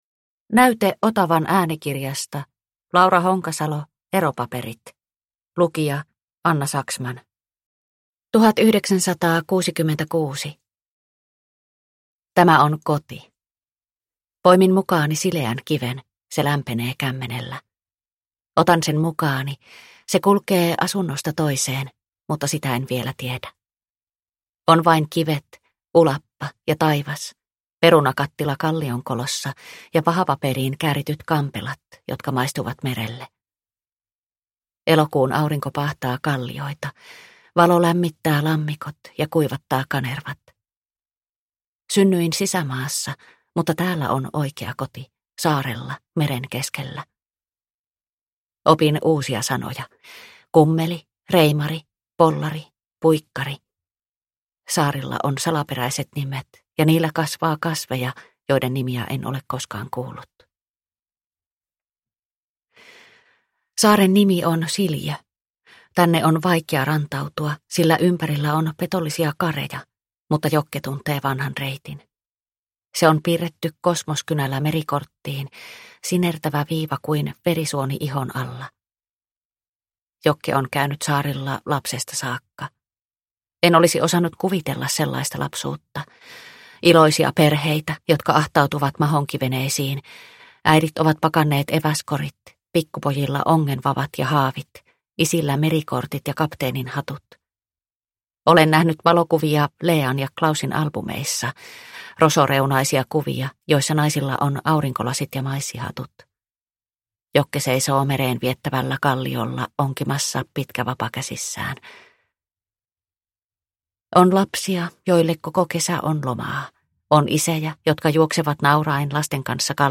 Eropaperit – Ljudbok – Laddas ner